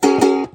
Звук мелодии укулеле
Мягкий звук укулеле превращает любой проект в настоящее чудо!
укулеле бренчание1
ukulele_brenchanie1_wcu.mp3